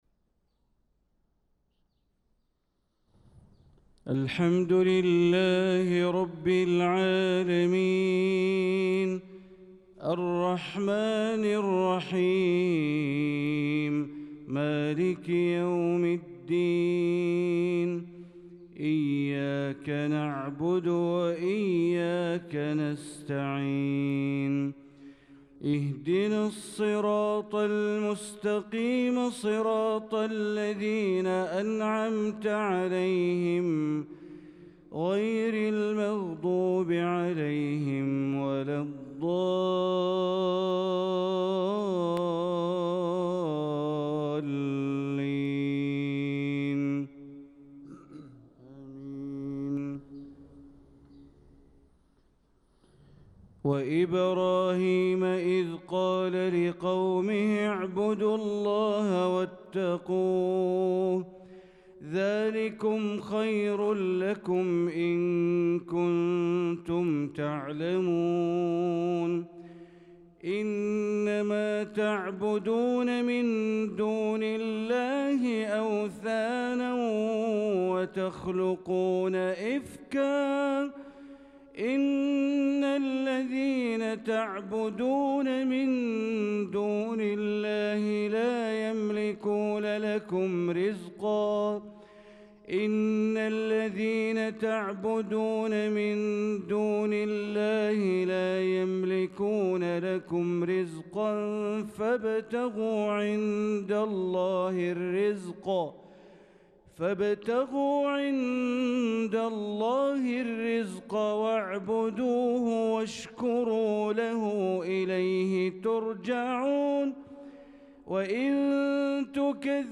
صلاة الفجر للقارئ بندر بليلة 24 ذو القعدة 1445 هـ